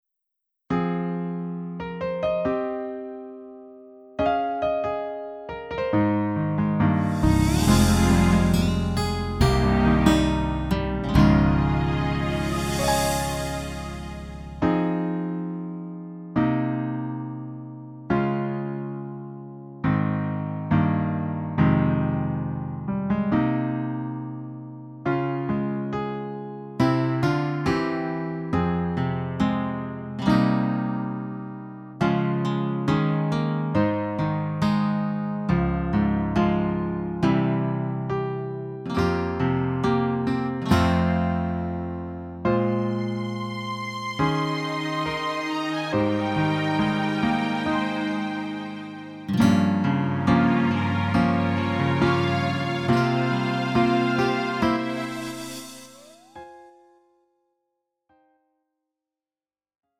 음정 -1키 3:44
장르 가요 구분 Lite MR